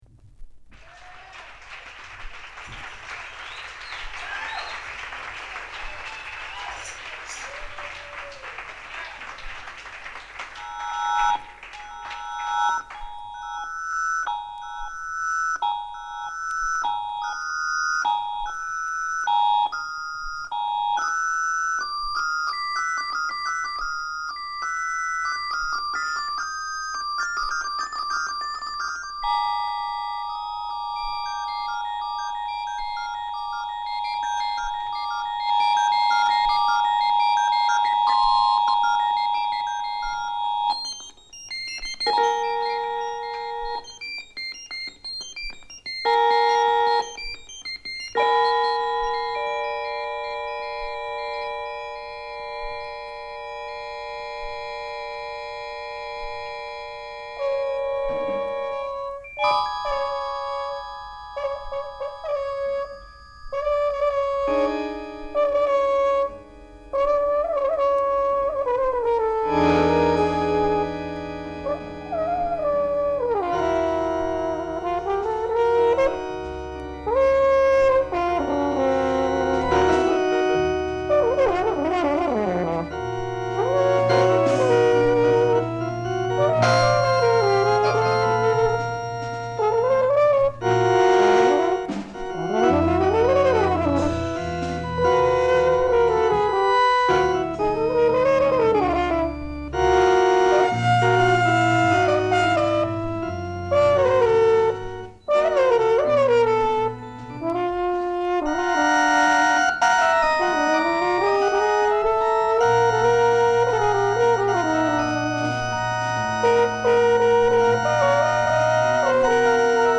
緩急のついたオルガンが強烈！